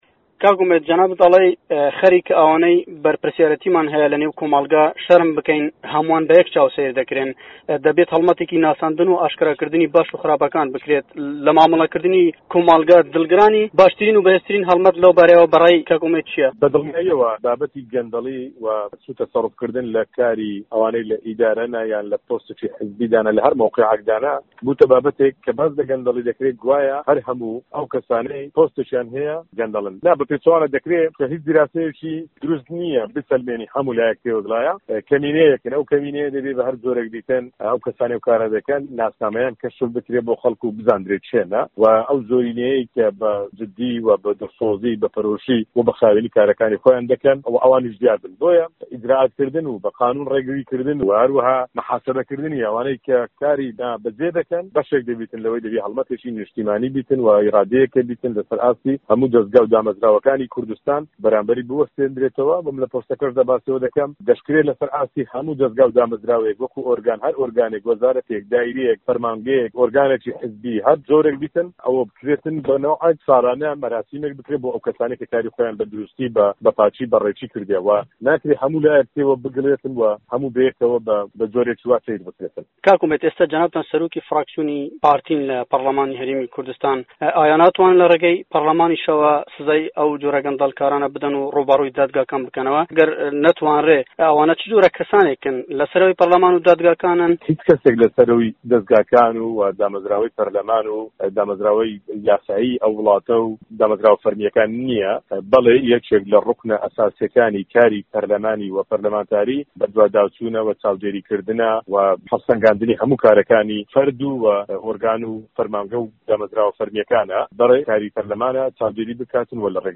وتووێژ لەگەڵ ئومێد خۆشناو